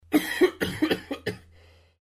cough6.mp3